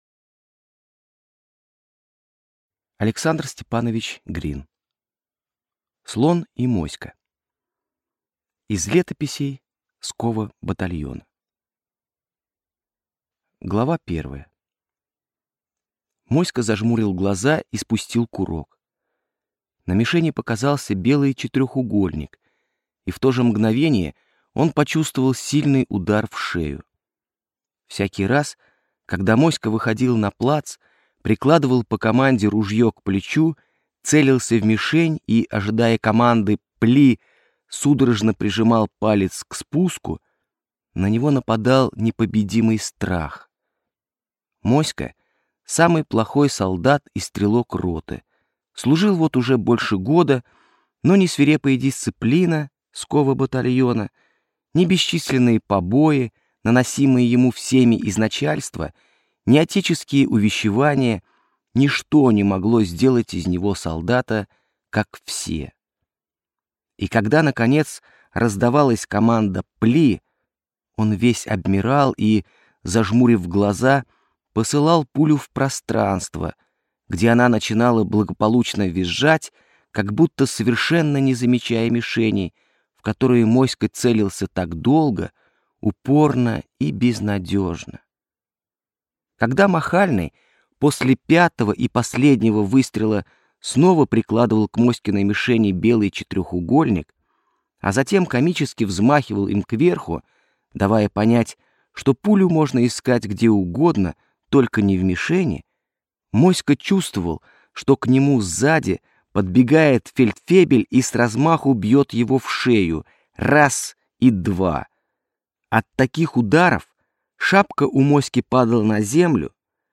Аудиокнига Слон и Моська | Библиотека аудиокниг